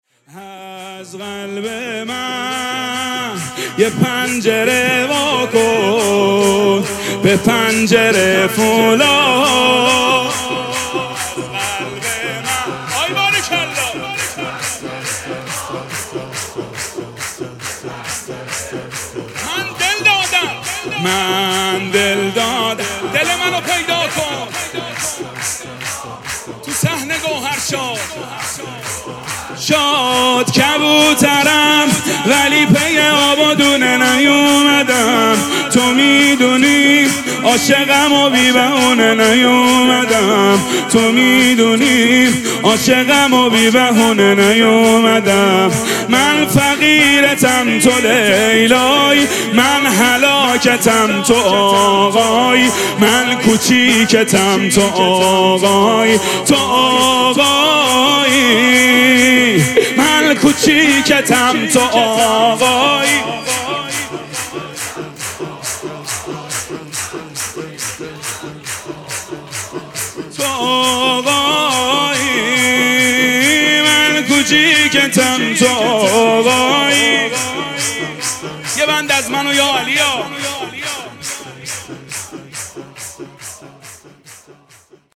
مراسم جشن ولادت امام رضا علیه‌السّلام
حسینیه ریحانه الحسین سلام الله علیها
سرود